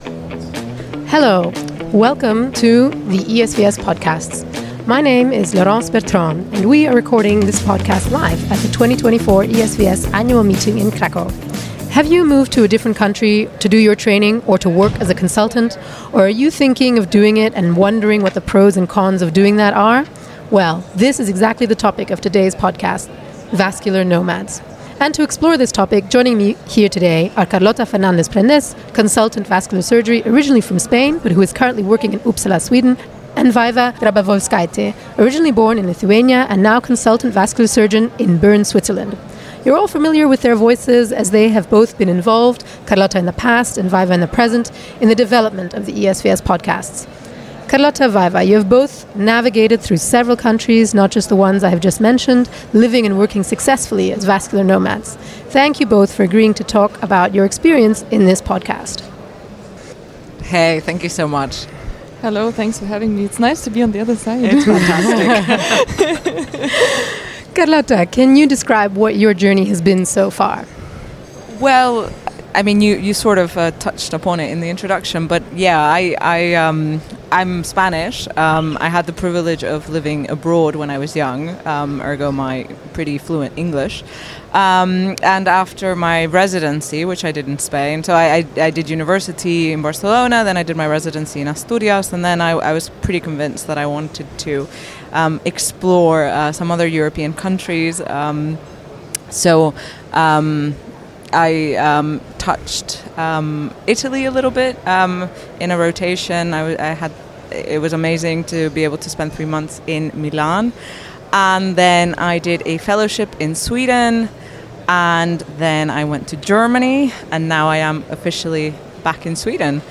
Recording from the 2024 ESVS Annual Meeting in Krakow